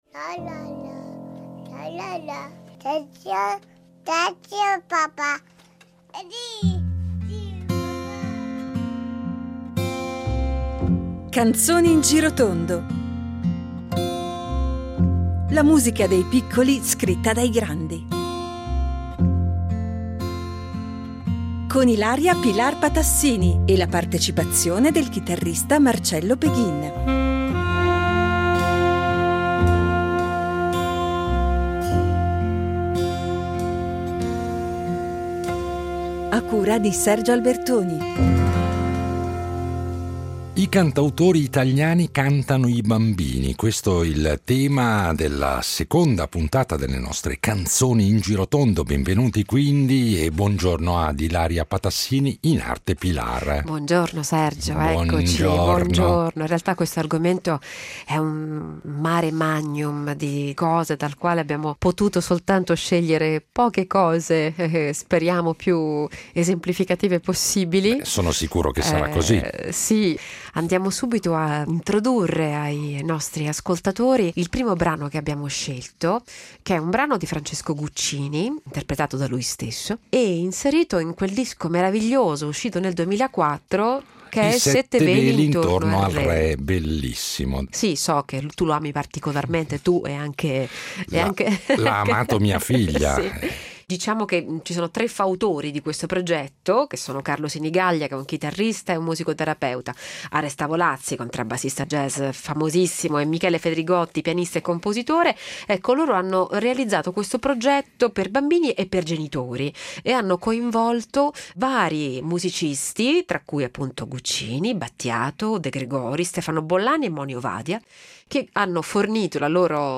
chitarrista